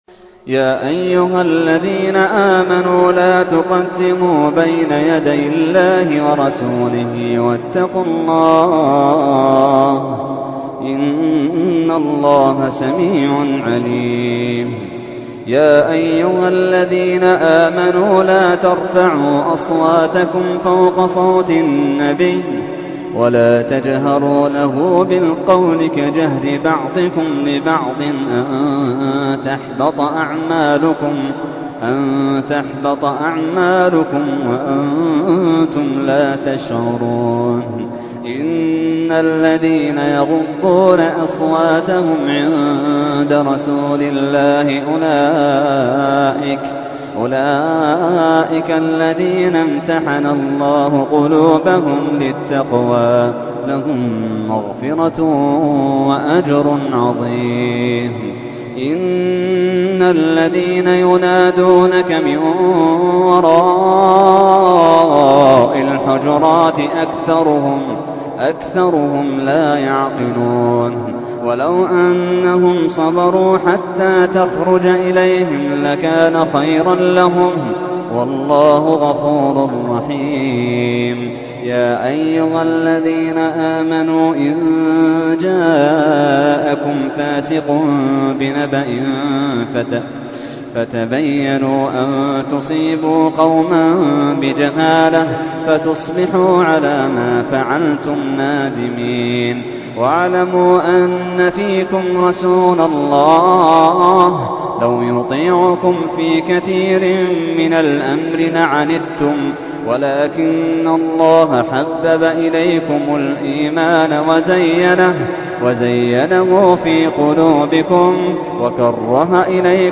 تحميل : 49. سورة الحجرات / القارئ محمد اللحيدان / القرآن الكريم / موقع يا حسين